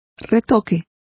Complete with pronunciation of the translation of retouch.